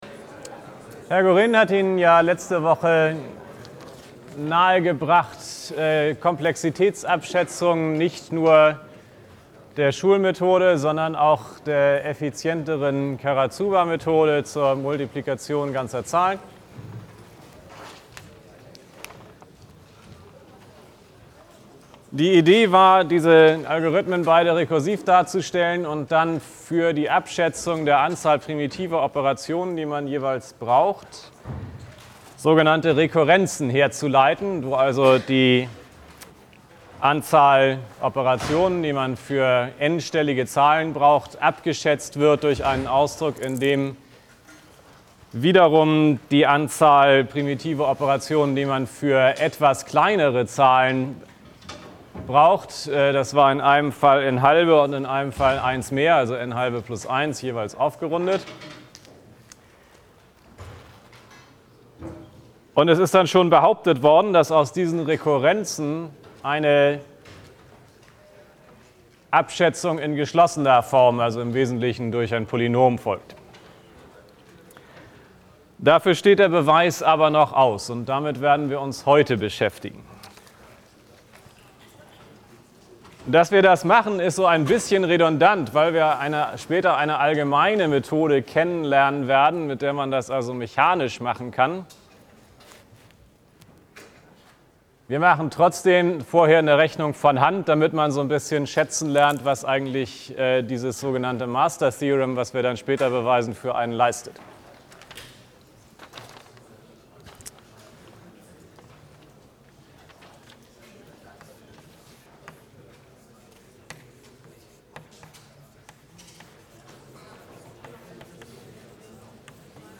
Vorlesungsaufzeichnungen am Department Informatik